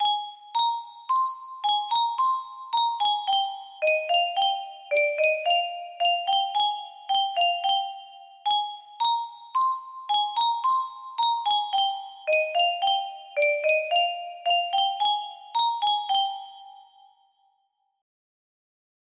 2 music box melody